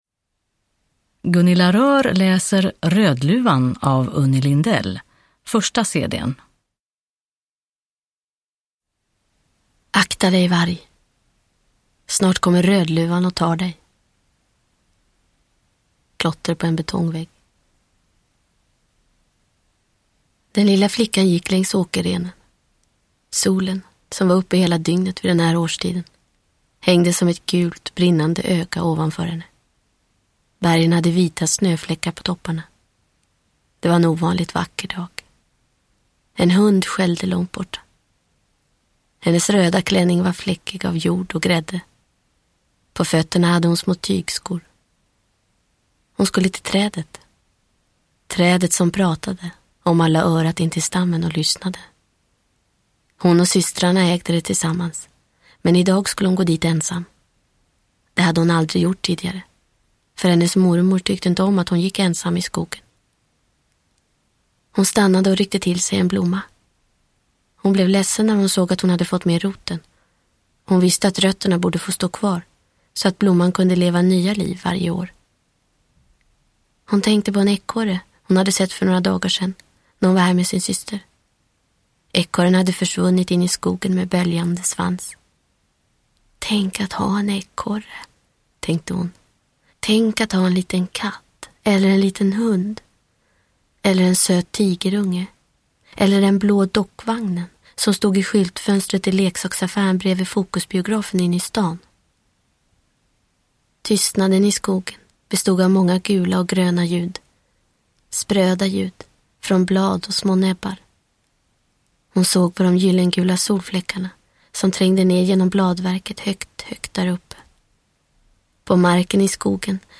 Rödluvan / Ljudbok